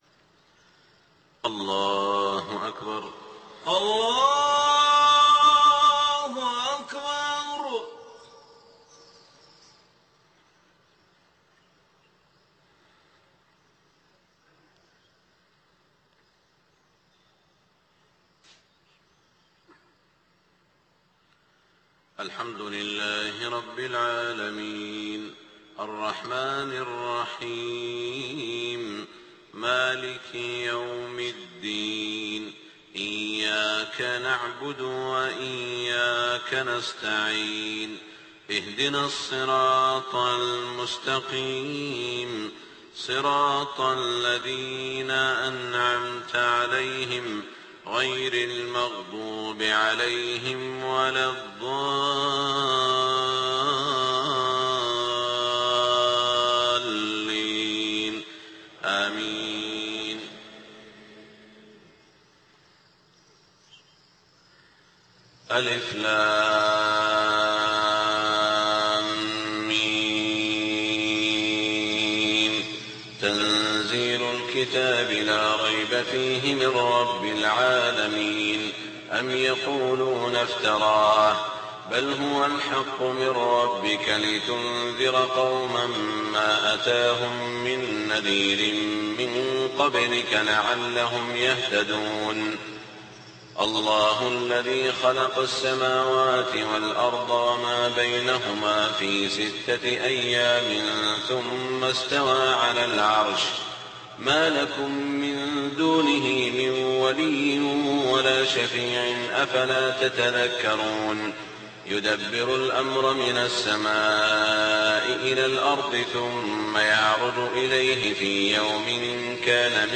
صلاة الفجر 4-3-1428هـ سورتي السجدة والإنسان > 1428 🕋 > الفروض - تلاوات الحرمين